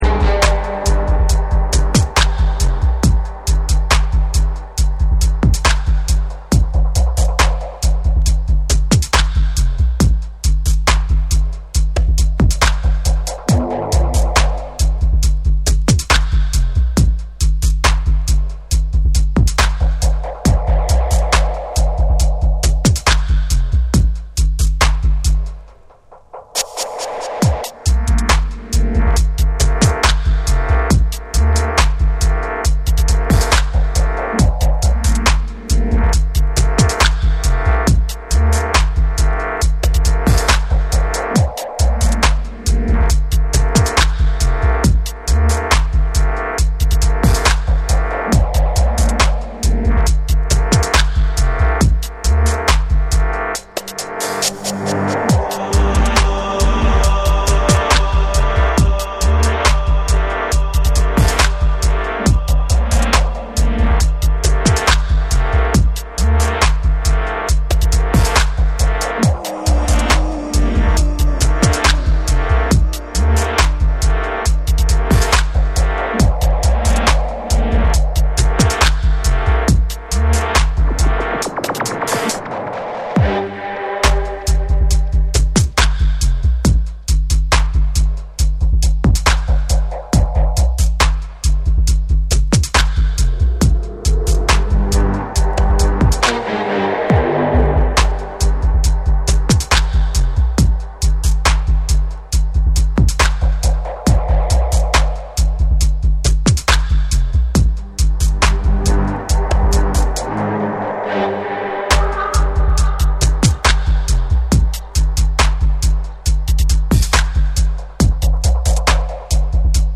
よりダークでミニマルな構成の中に緊張感を孕んだディープなダブステップを披露っする
BREAKBEATS / DUBSTEP